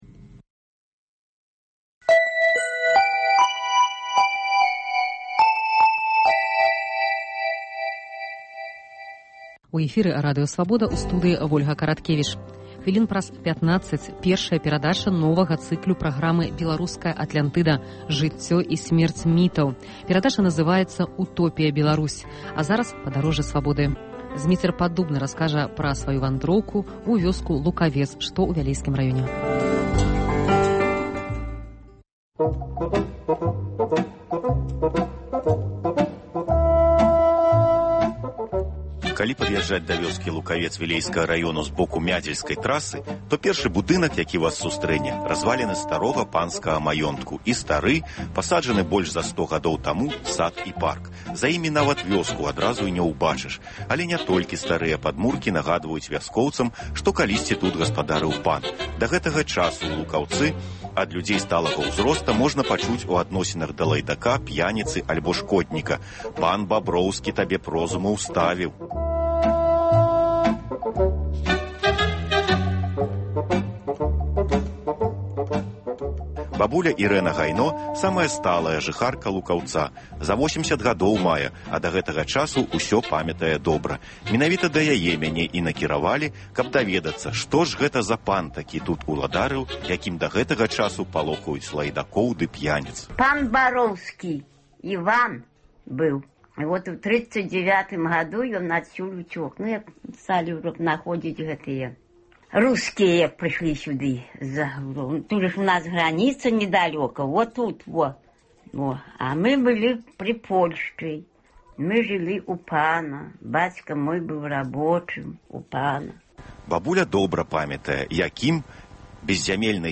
Normal 0 Паездкі нашых карэспандэнтаў па гарадах і вёсках Беларусі.